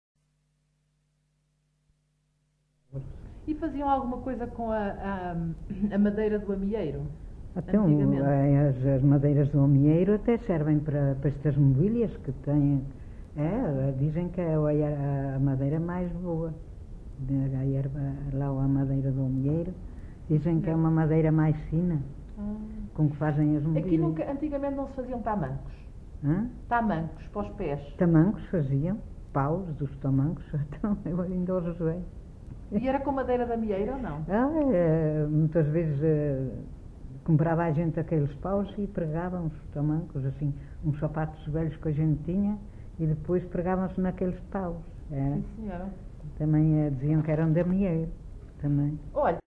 Assanhas (Figueiró da Serra), excerto 16
LocalidadeAssanhas (Celorico da Beira, Guarda)